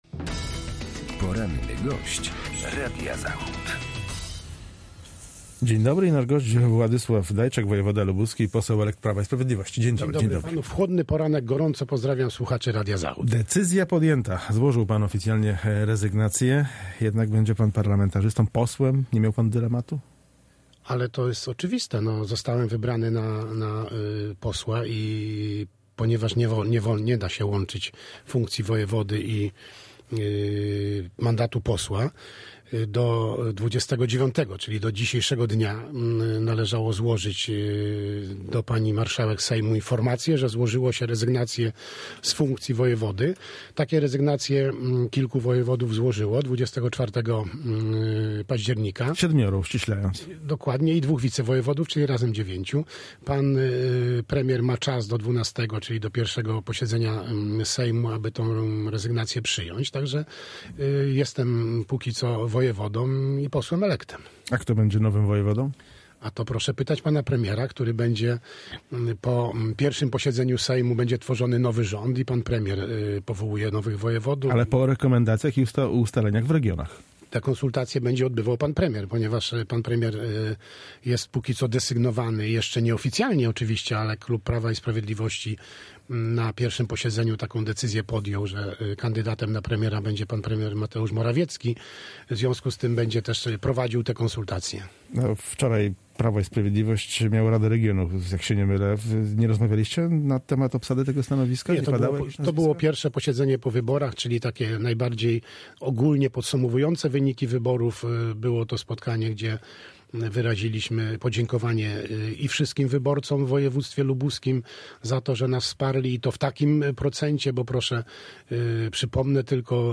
Z wojewodą lubuskim rozmawia